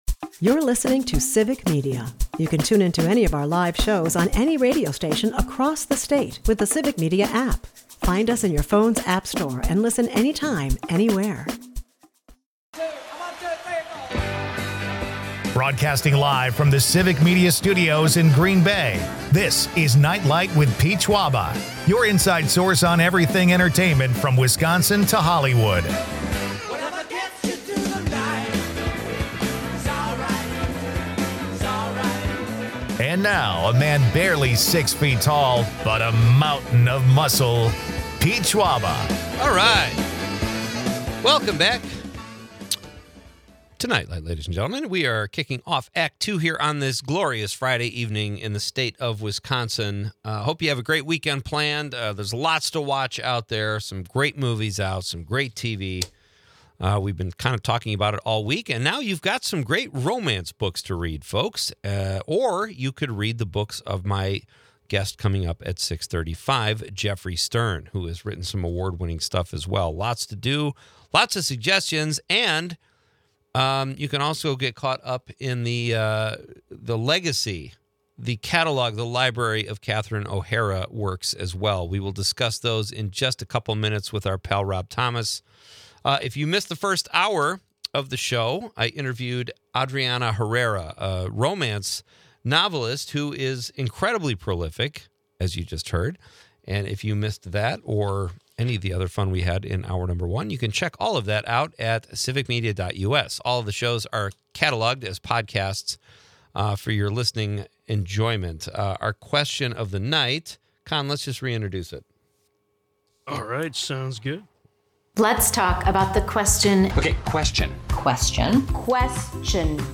The conversation then shifts to aliens, as listeners and guests share their favorite extraterrestrial films and shows, from 'Arrival' to 'The X-Files.'